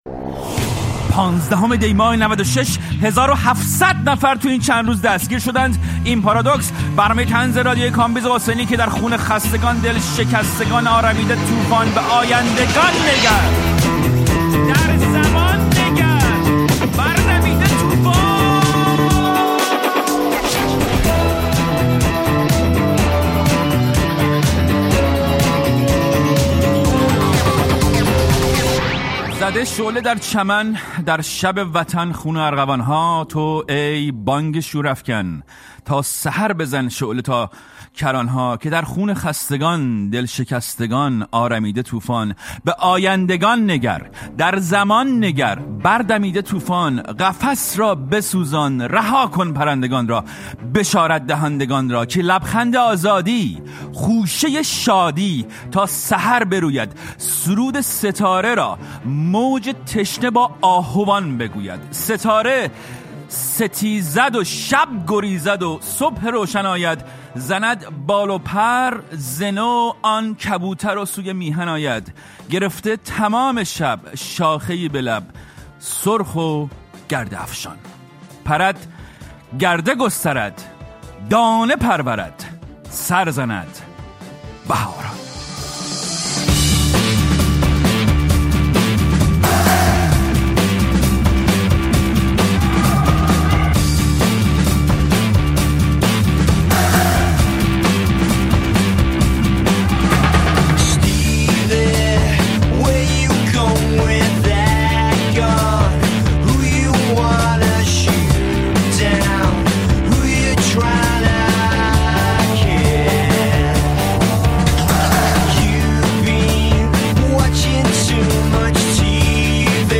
پارادوکس با کامبیز حسینی؛ گفت‌وگو با شیرین نشاط